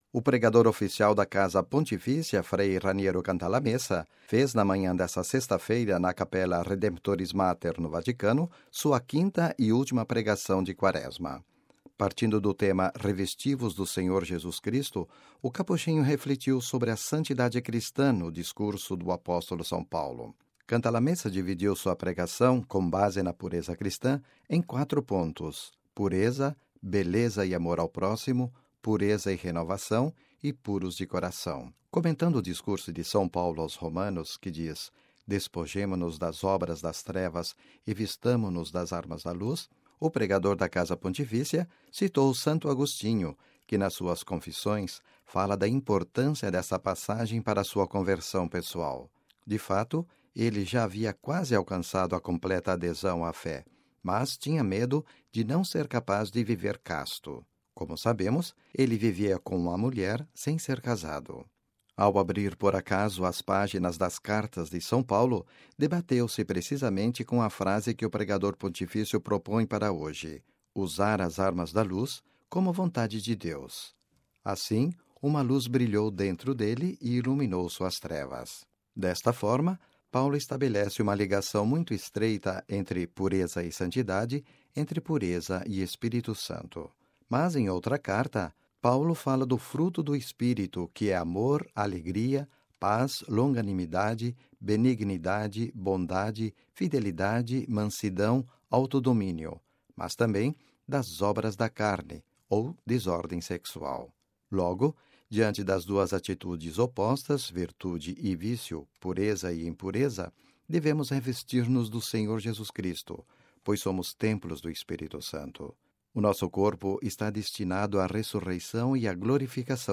O pregador oficial da Casa Pontifícia, frei Raniero Cantalamessa, fez na manhã desta sexta-feira (23/03) na Capela Redemptoris Mater, no Vaticano, sua quinta e última pregação da Quaresma.